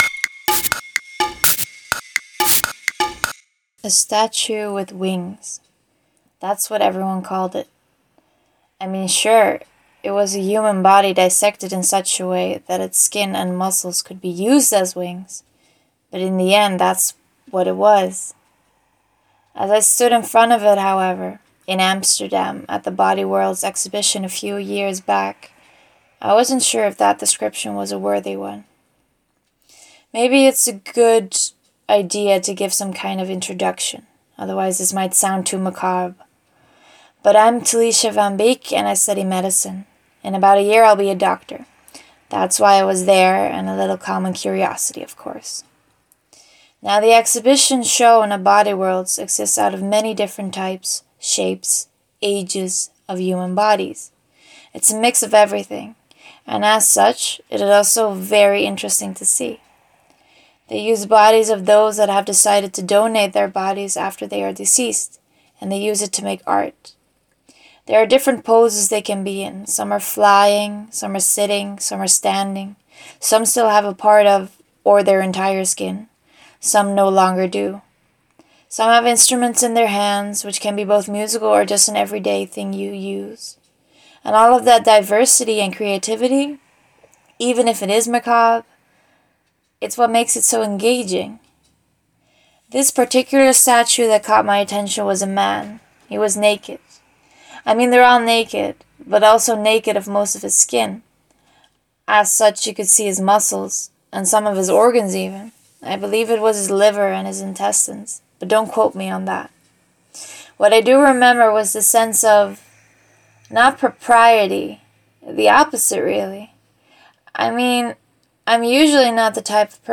The Invisible Collection is an art collection of stories in which people talk about their favorite work of art.